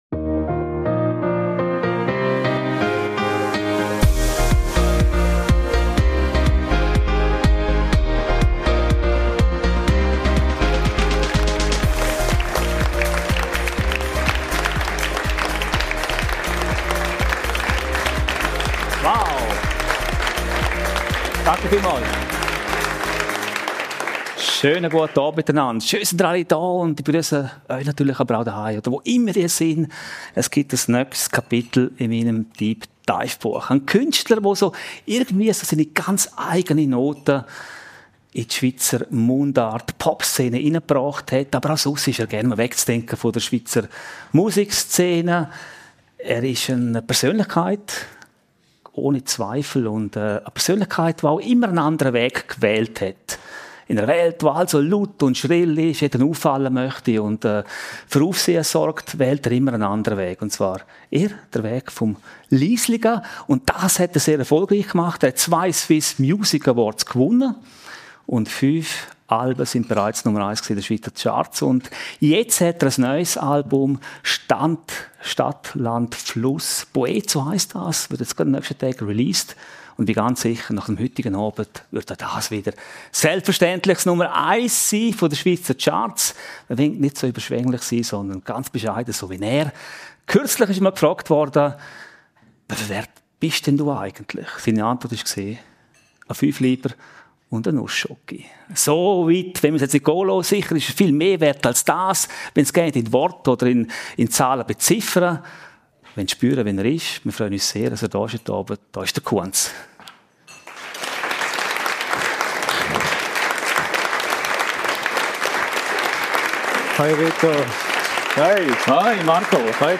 Die Episode wurde live vor Publikum im From Heaven in Bad Ragaz aufgezeichnet.